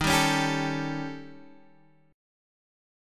Eb7#11 chord